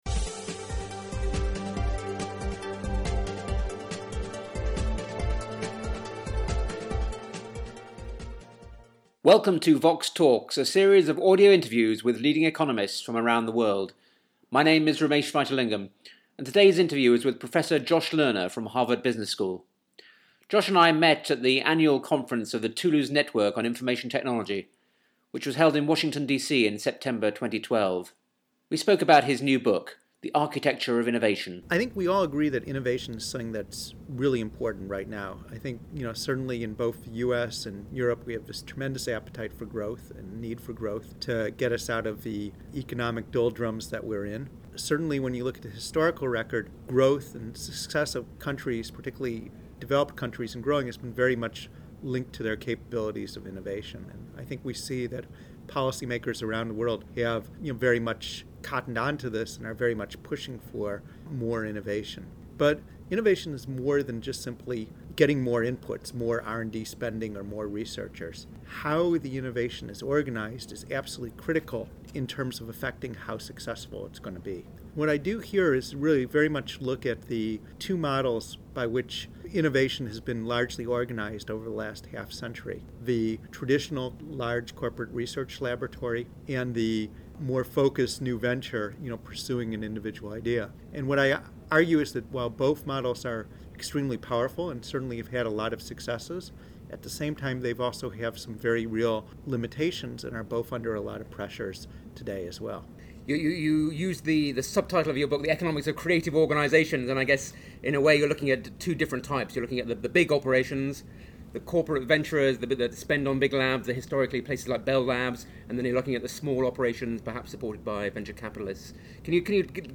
They discuss a variety of issues around the challenges of innovation, including corporate venturing, venture capital-based enterprises, patents and public investment in science. The interview was recorded in Washington, DC, at the annual meeting of the Toulouse Network on Information Technology in September 2012.